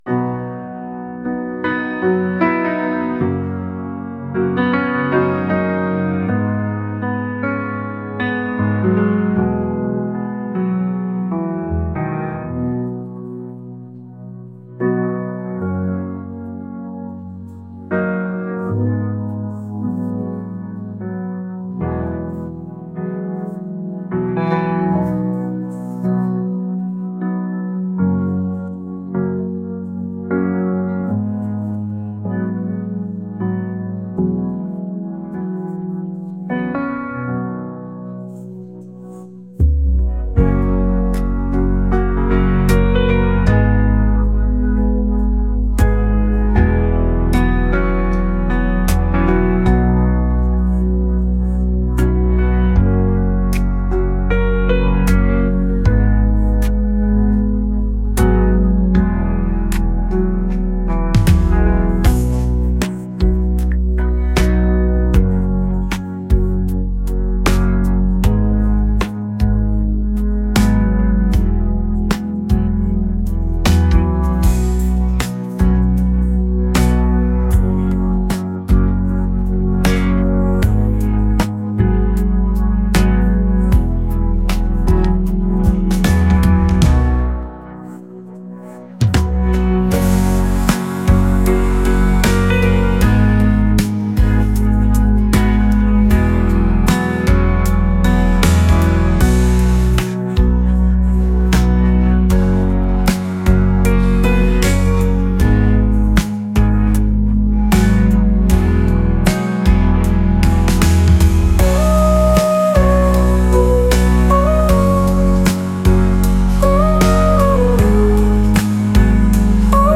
h, f#, g#, e